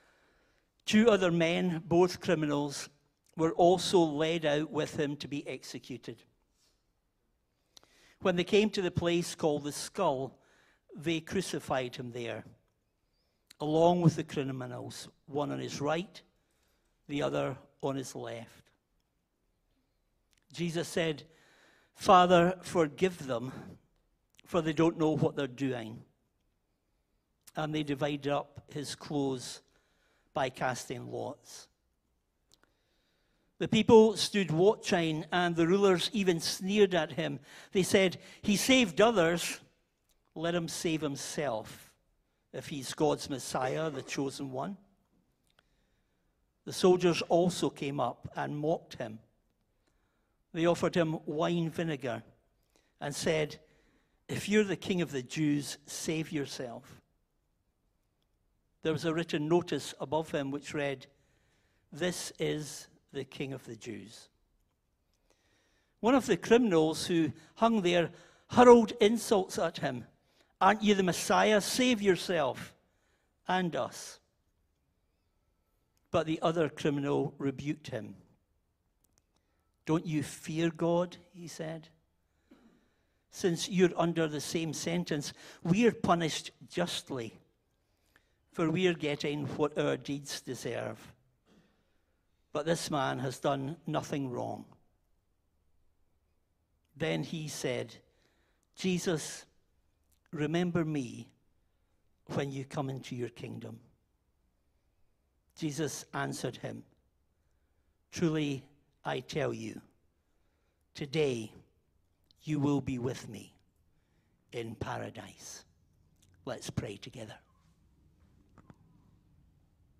Sermons | Trinity Church of the Nazarene